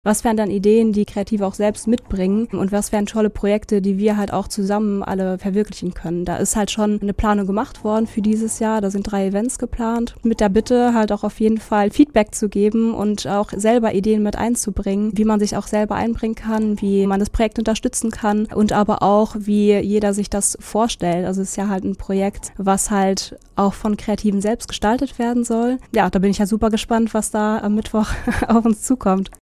MITSCHNITT AUS DER SENDUNG